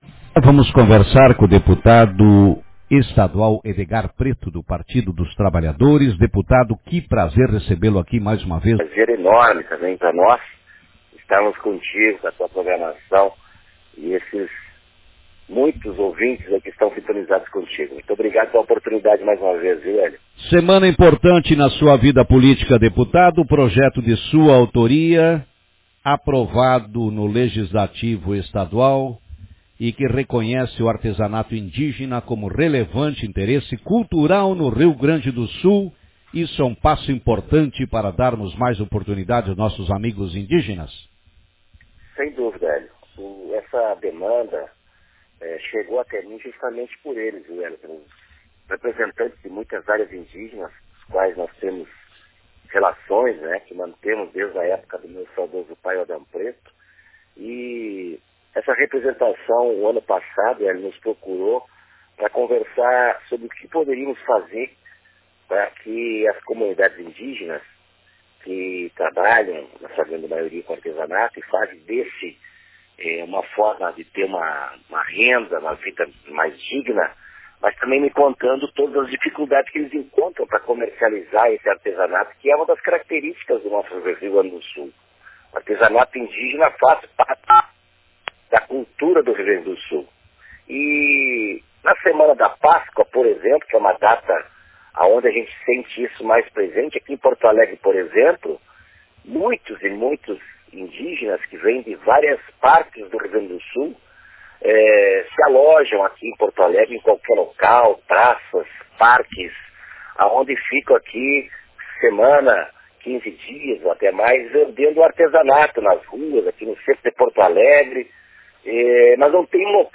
Deputado Estadual, Edegar Pretto – PT/RS, fala sobre a aprovação de projeto de lei de sua autoria que beneficia indígenas gaúchos
entrevista-edgar-pretto.mp3